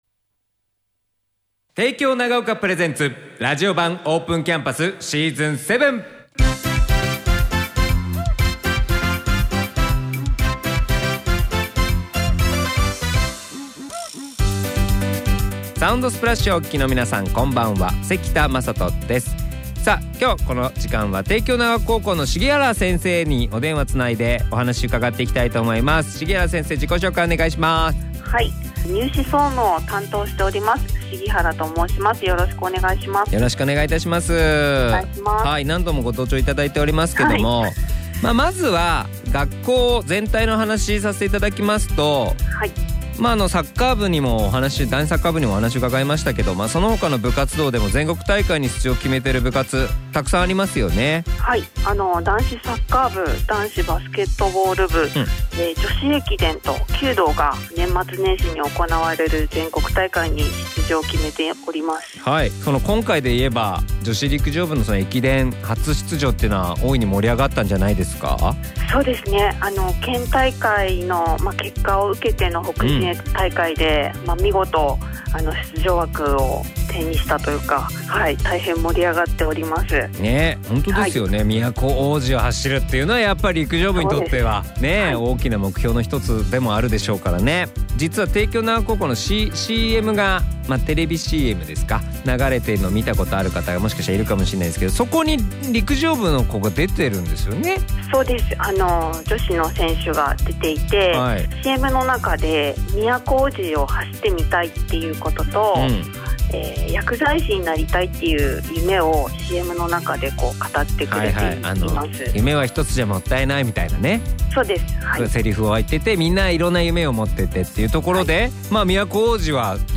ラジオ版オープンキャンパス